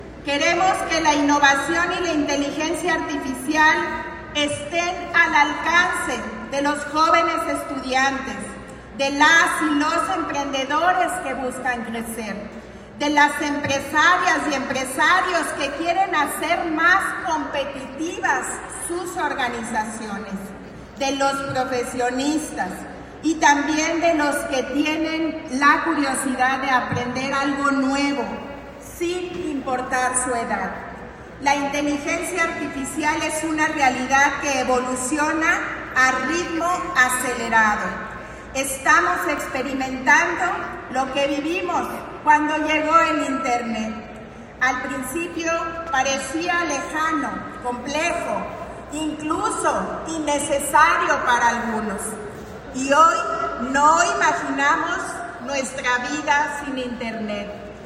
Lorena Alfaro García, presidenta de Irapuato